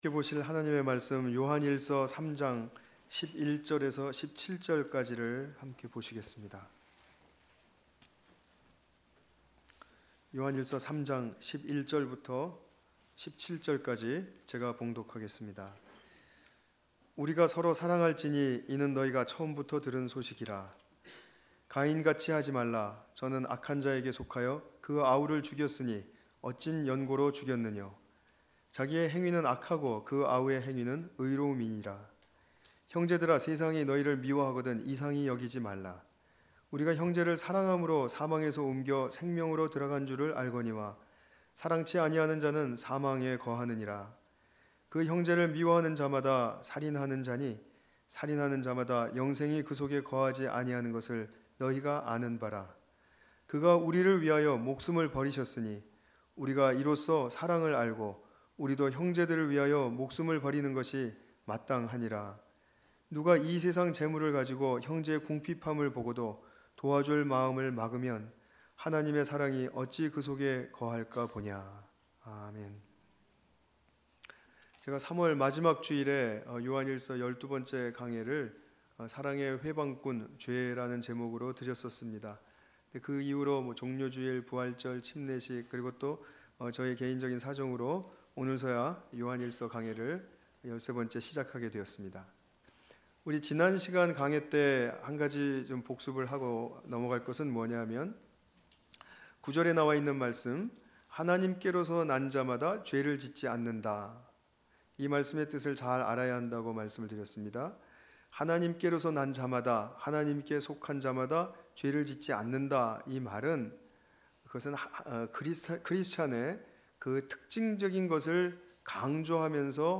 요한일서 3장 11-17절 관련 Tagged with 주일예배